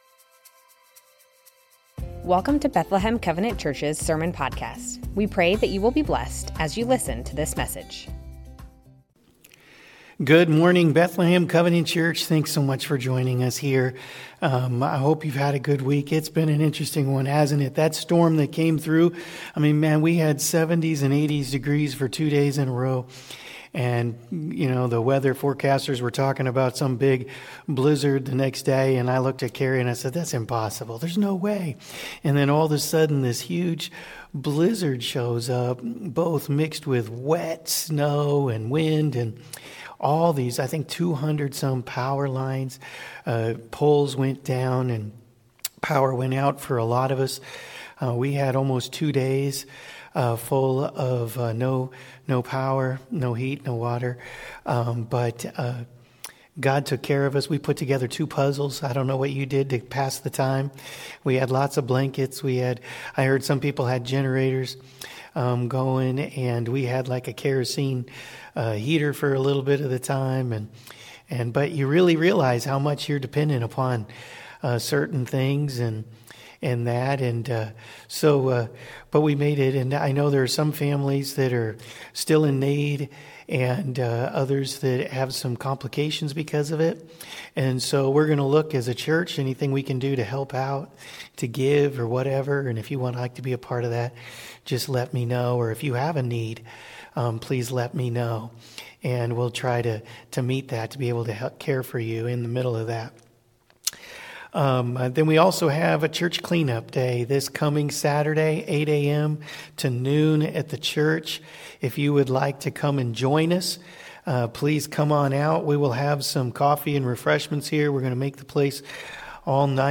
Bethlehem Covenant Church Sermons Matthew 8:1-17 - Jesus Heals Mar 24 2025 | 00:36:09 1x 00:00 / 00:36:09 Subscribe Share Spotify RSS Feed Share Link Embed